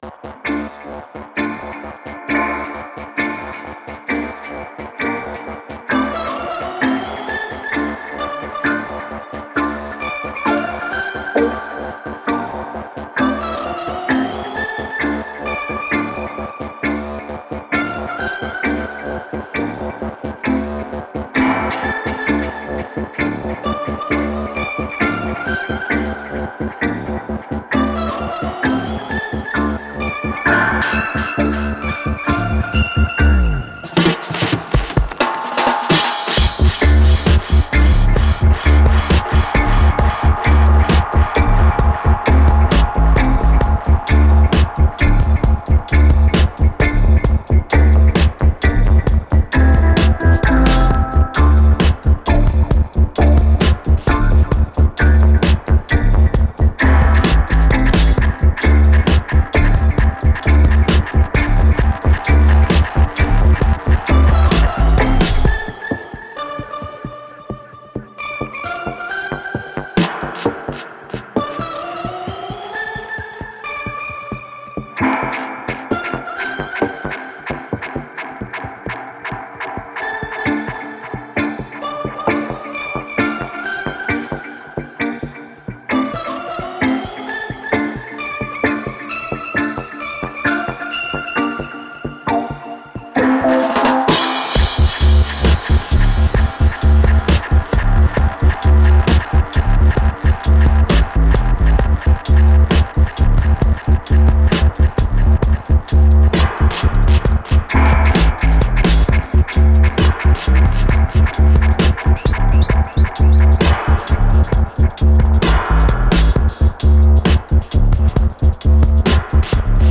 Badass bassline dub with a psychedelic organ theme.